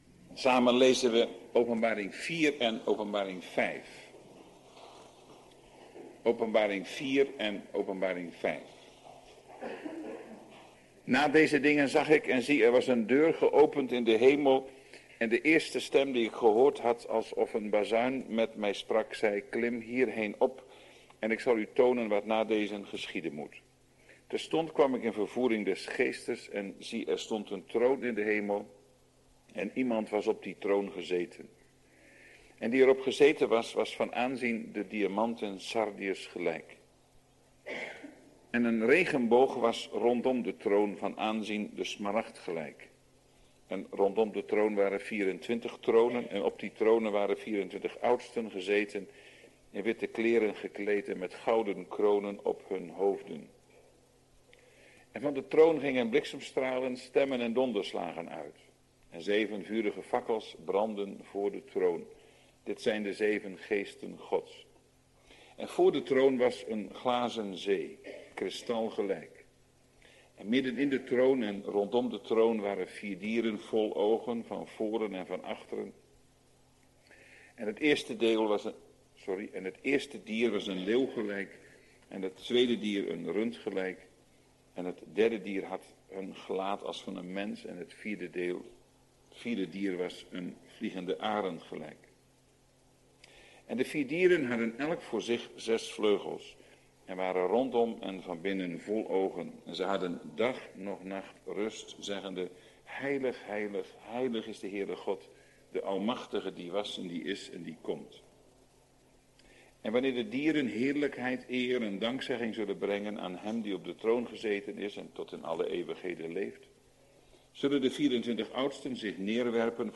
Na ongeveer 45 minuten (wanneer kant A overgaat naar kant B) kan een korte stilte voorkomen.